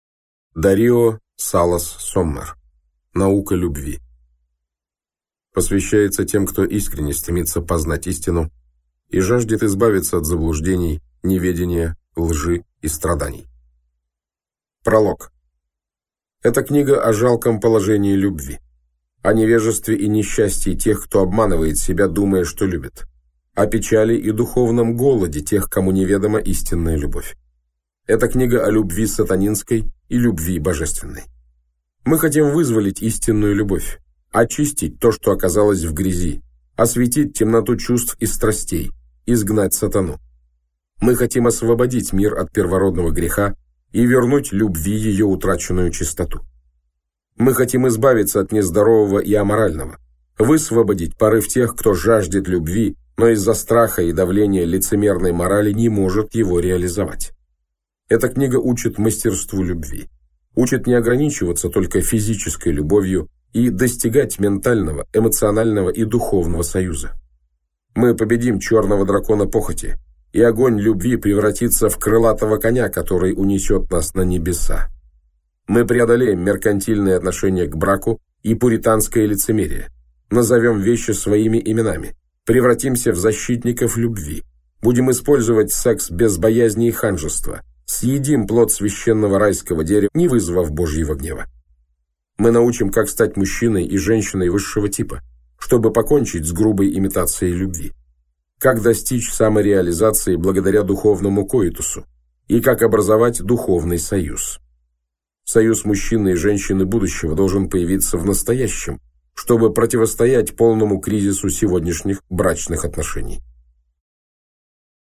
Аудиокнига Наука Любви | Библиотека аудиокниг
Aудиокнига Наука Любви Автор Дарио Салас Соммэр Читает аудиокнигу Александр Клюквин.